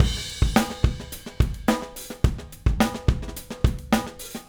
Retro Funkish Beat 01 Crash.wav